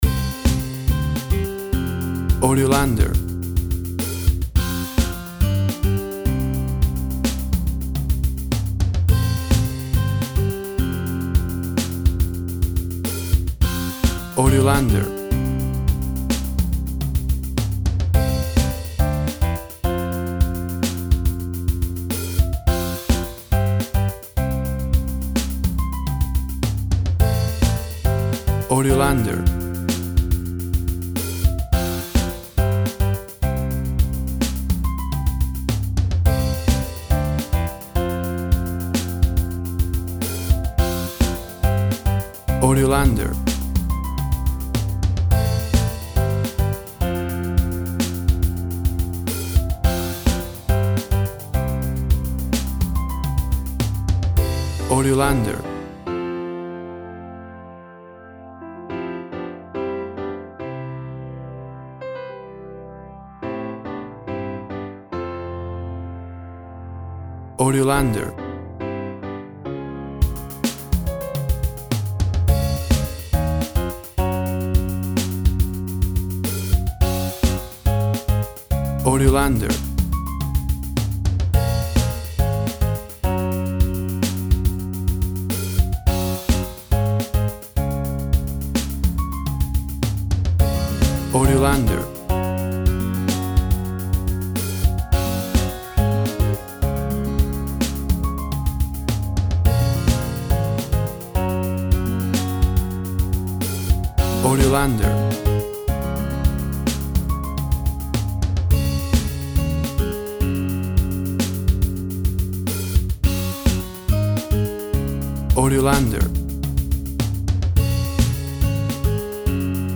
WAV Sample Rate 16-Bit Stereo, 44.1 kHz
Tempo (BPM) 100